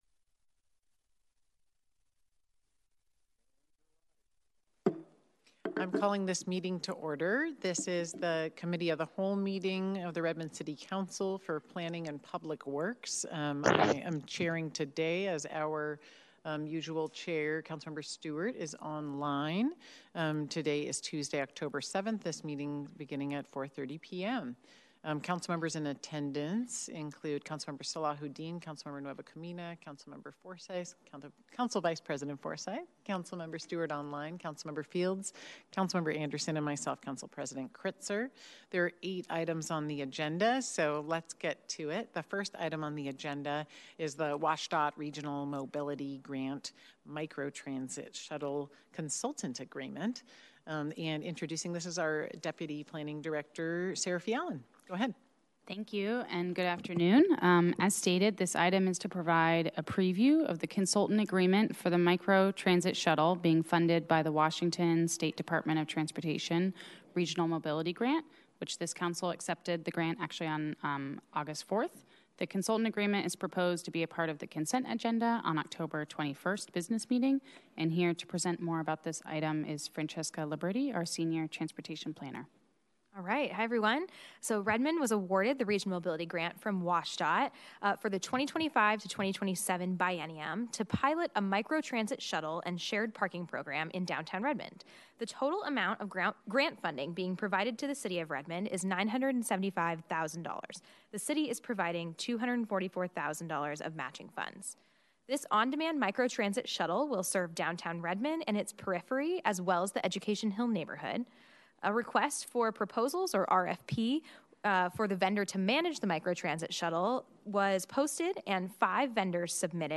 Committee of the Whole - Planning and Public Works on 2025-10-07 4:30 PM - Oct 07, 2025